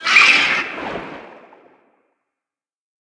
Sfx_creature_seamonkey_flare_01.ogg